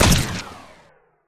lifeimpact04.wav